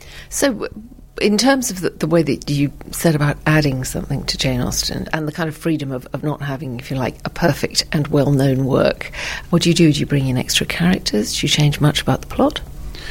【英音模仿秀】《爱情与友谊》 听力文件下载—在线英语听力室